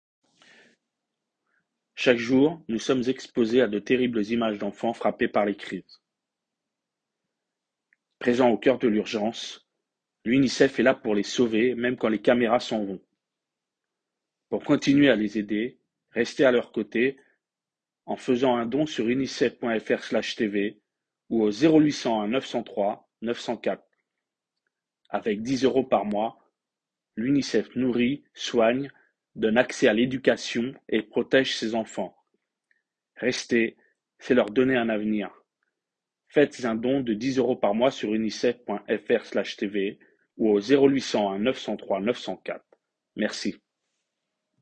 voix off unicef
NKzpDOLe3Dx_Voix-off-unicef-1-.m4a